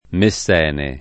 [ me SS$ ne ]